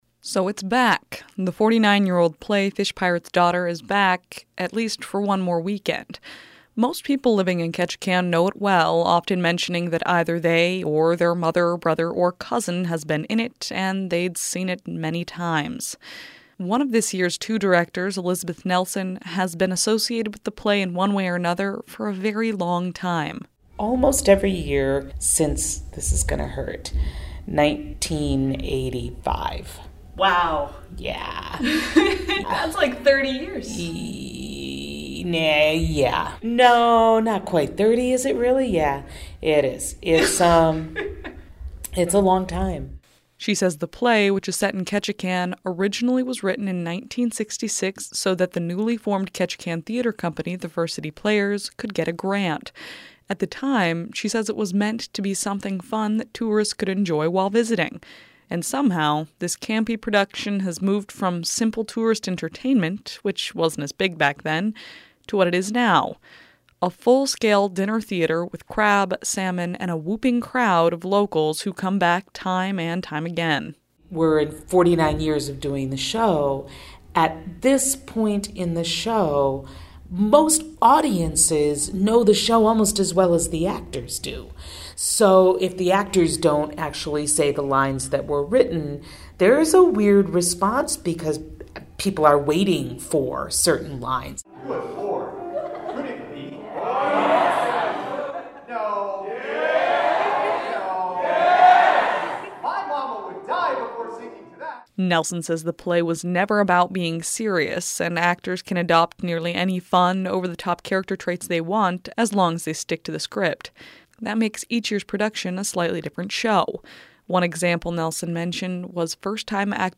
(Pause, then audience laughter.)